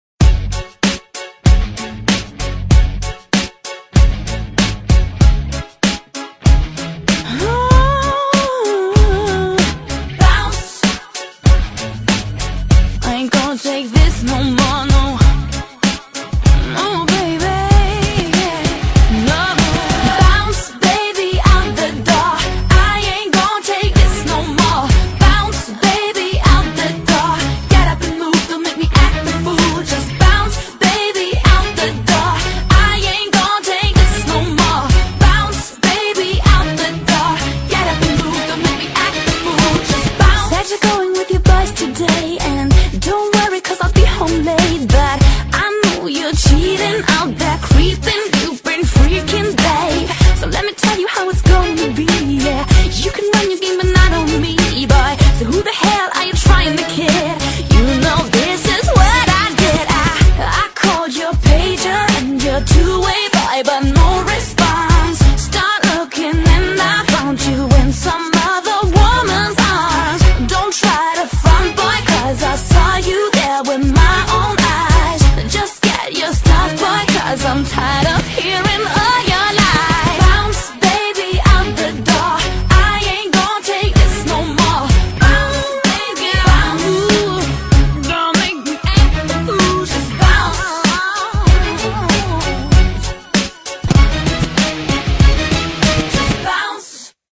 BPM96--1
Audio QualityPerfect (High Quality)